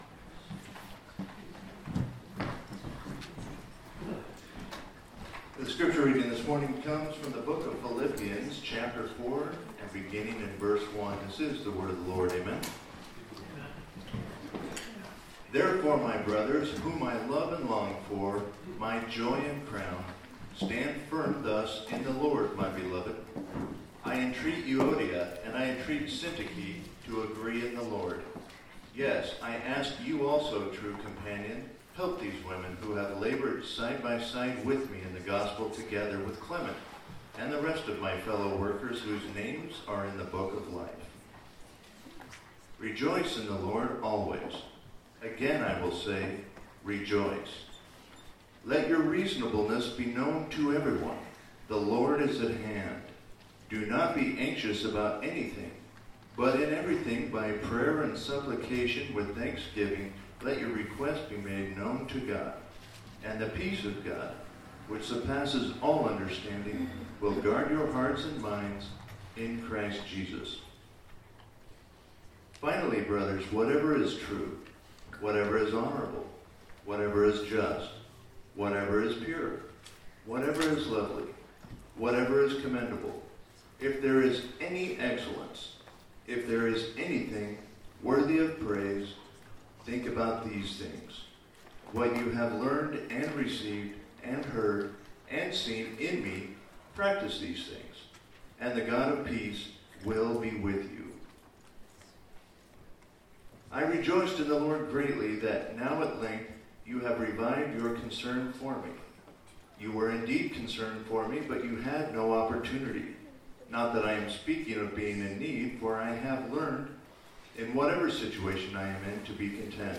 Passage: Philippians 4:1-9 Service Type: Sunday Morning